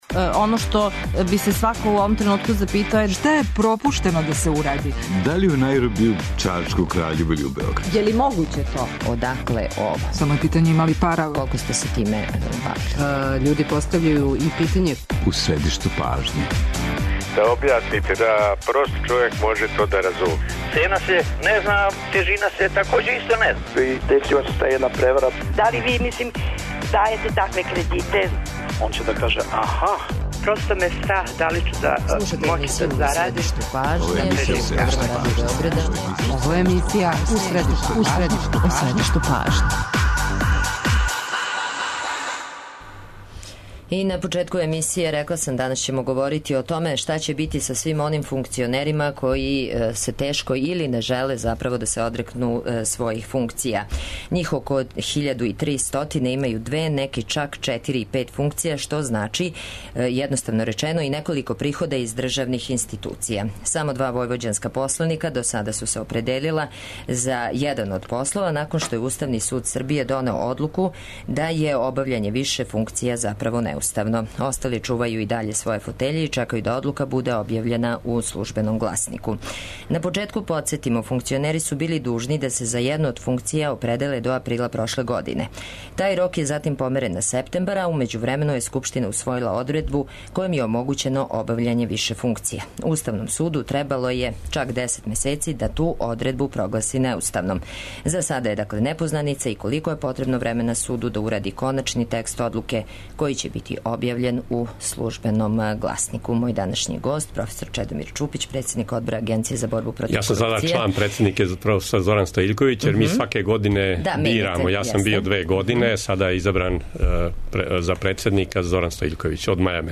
Гост емисије биће Чедомир Чупић, председник Одбора Агенције за борбу против корупције.